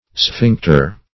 Sphincter \Sphinc"ter\, n. [NL., fr. ??? to bind tight.] (Anat.)